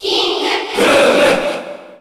Category: Crowd cheers (SSBU) You cannot overwrite this file.
King_K._Rool_Cheer_Japanese_SSBU.ogg